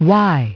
Y, y – [ wahy ] – / waɪ /